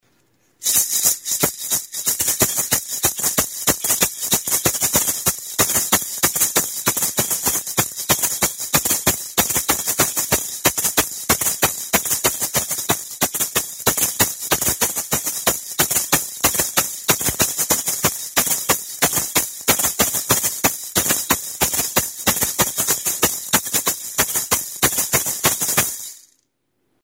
Music instrumentsMARACAS
Idiophones -> Struck -> Maracas / rattles
Recorded with this music instrument.
Kokoen azal gogorrarekin eta zurezko kirtenarekin egindako bi maraka dira. Astintzerakoan, barruan dituzten hazi aleek hotsa ematen dute.